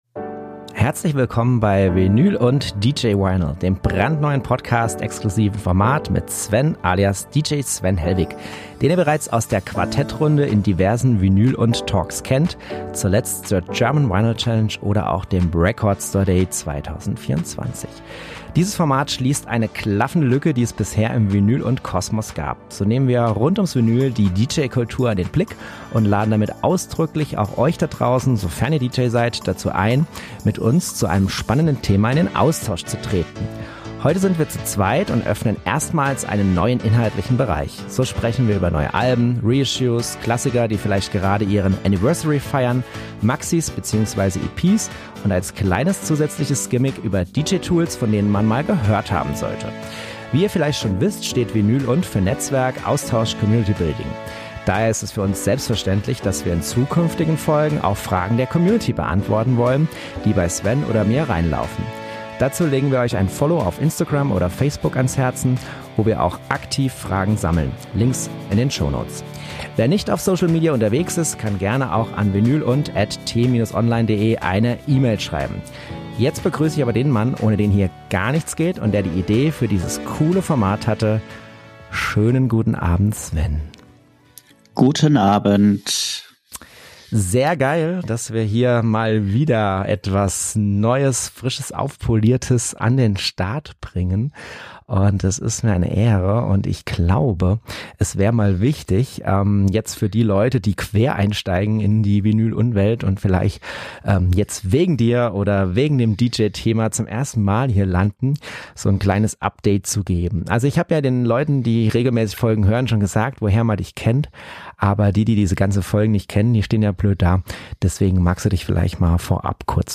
Heute sind wir zu zweit und öffnen erstmals einen neuen inhaltlichen Bereich. So sprechen wir über neue Alben, Reissues, Klassiker, die vielleicht gerade ihren Anniversary feiern, Maxis bzw. EP's und als kleines zusätzliches Gimmick über DJ Tools, von denen man mal gehört haben sollte.